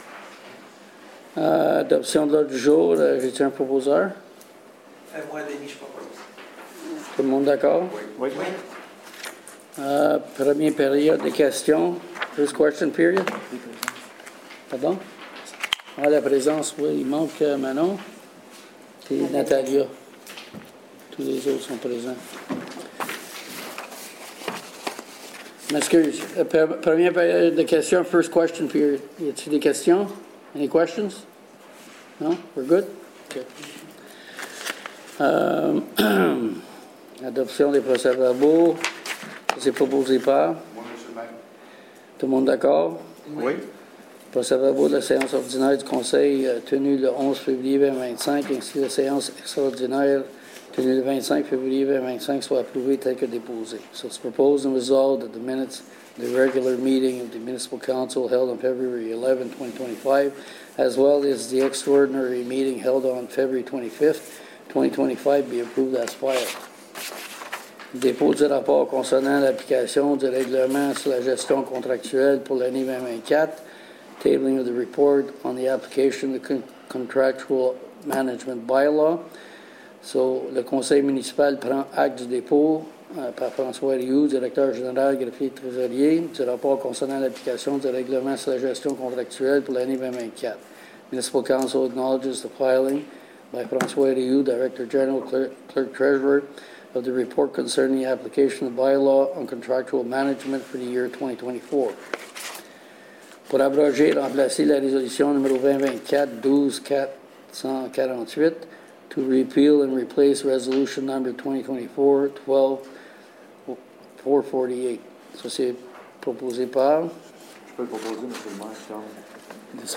SÉANCE DU 11 MARS 2025/SESSION OF MARCH 11, 2025